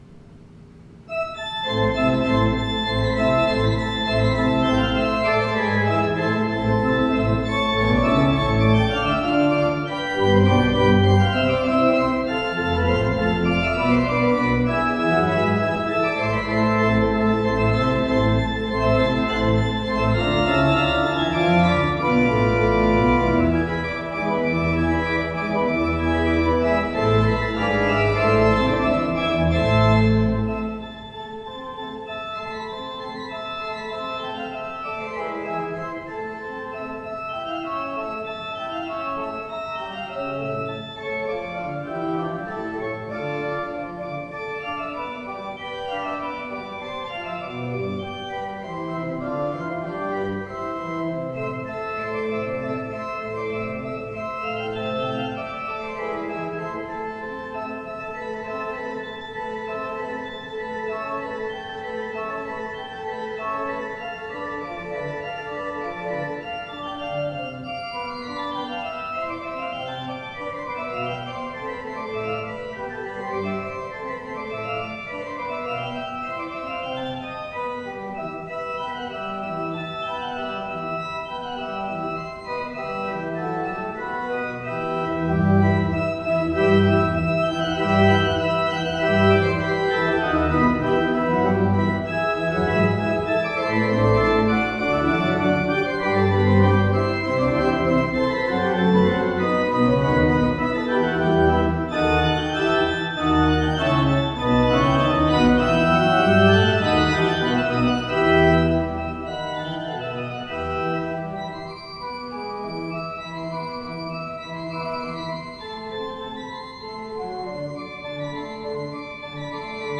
The sound files listed below are not live service recordings due to obvious logistic difficulties. The recordings are taken from rehearsal tapes made in the week prior to the service in question.
Third Sunday in Easter